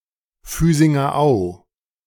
The Füsinger Au (German pronunciation: [ˈfyzɪŋɐ ˈaʊ̯]